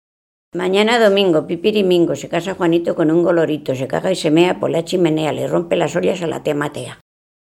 Clasificación: Dictados tópicos
Lugar y fecha de recogida: Baños de río Tobía, 30 de octubre de 2003